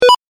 ボタン・システム （87件）
決定17.mp3